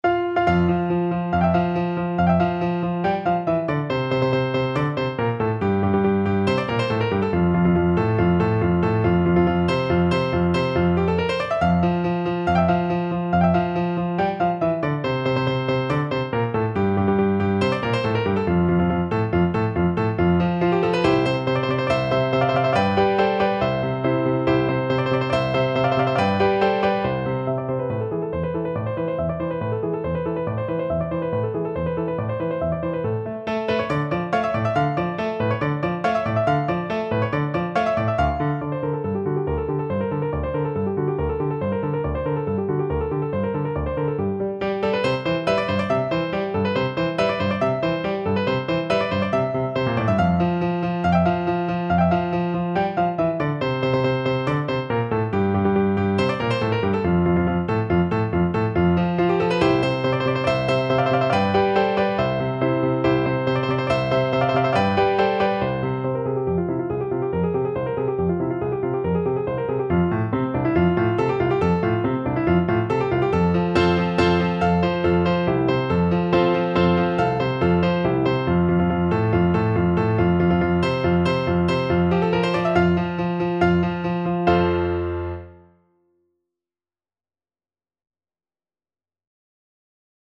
Play (or use space bar on your keyboard) Pause Music Playalong - Piano Accompaniment Playalong Band Accompaniment not yet available transpose reset tempo print settings full screen
~ = 140 Allegro vivace (View more music marked Allegro)
F major (Sounding Pitch) (View more F major Music for Flute )
Classical (View more Classical Flute Music)